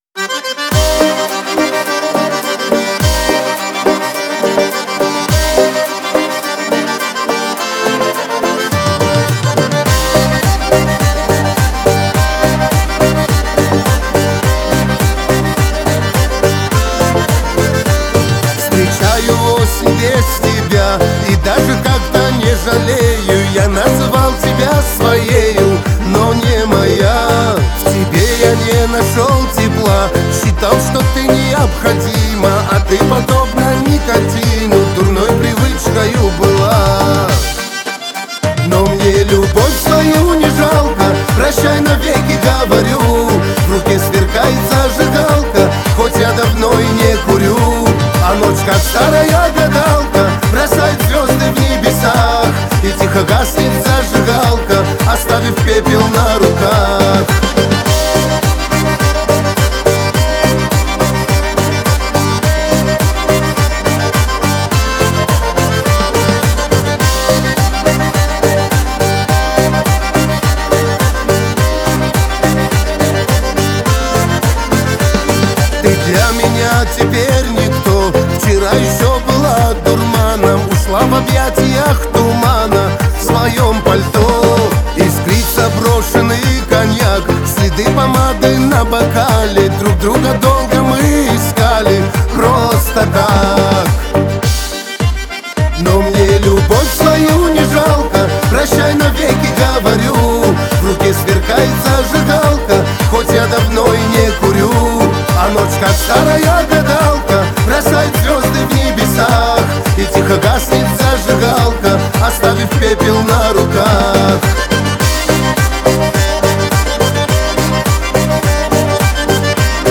это зажигательная композиция в жанре поп-фолк